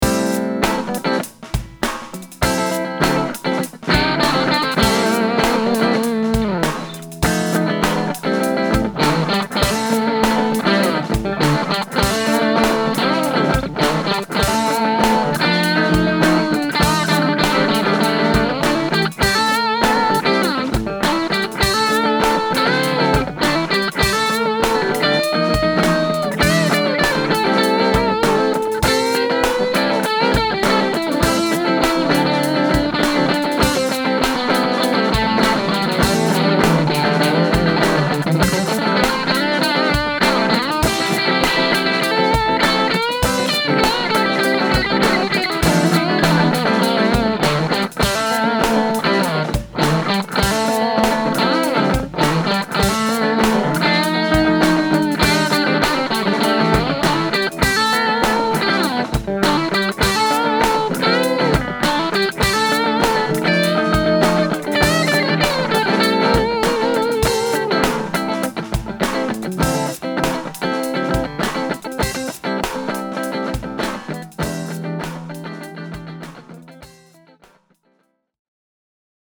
• Bridge clean and dirty
That’s most evident when playing a funky, clean riff. Combine that with an incredibly smooth and refined lead tone, and you’ve got a guitar that can create all sorts of tones!